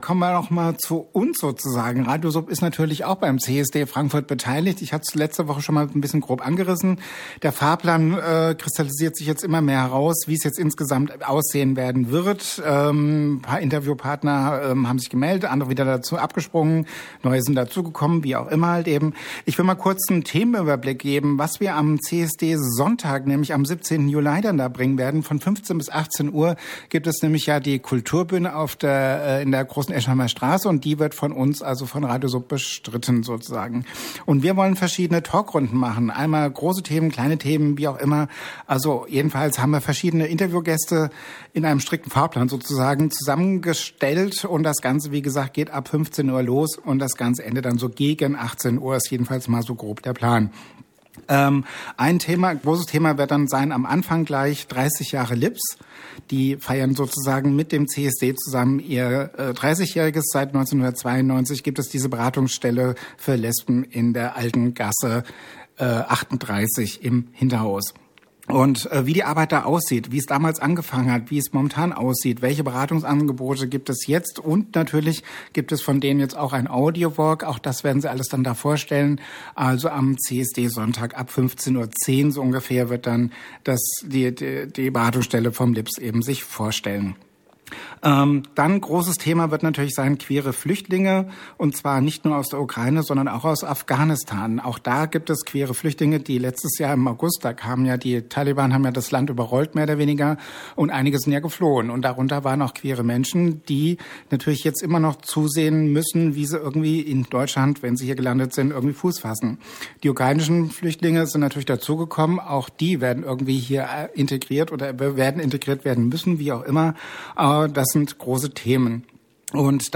radioSUB auf dem CSD
Interview